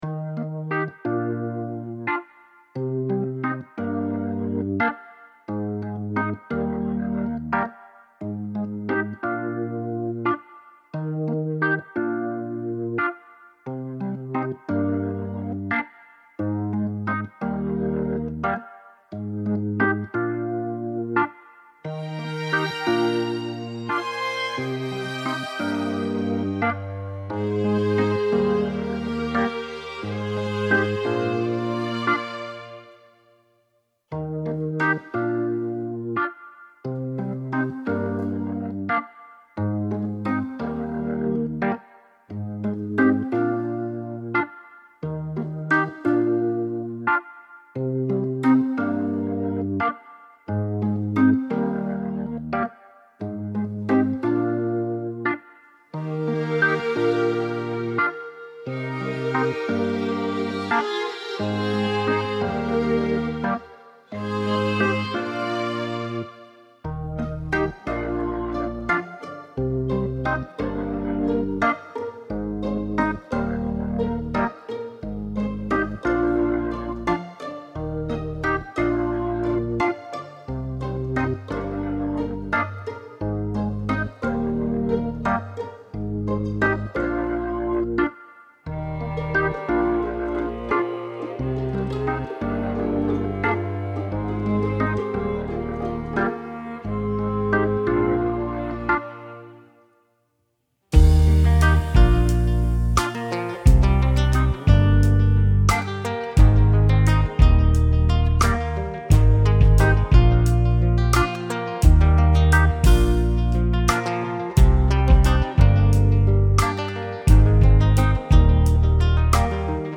Lyrics and Backing Tracks